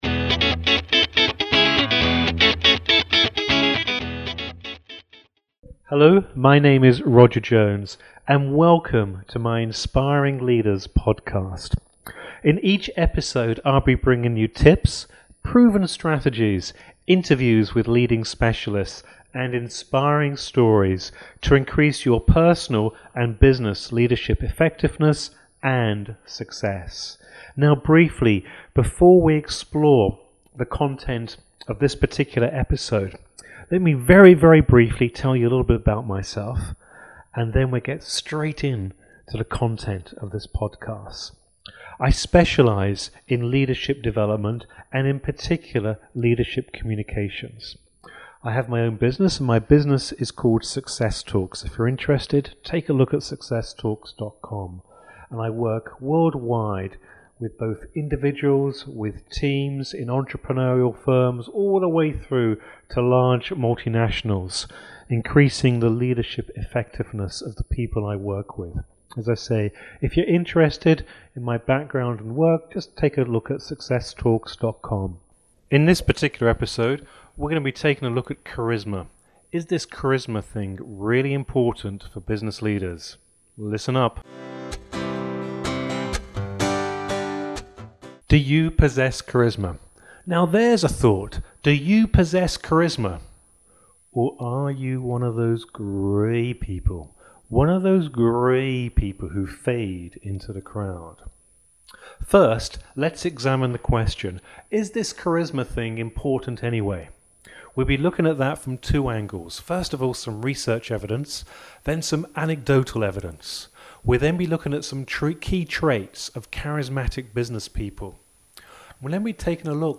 Each podcast contains a mix of highly informative articles, stories and interviews delivered in an entertaining style.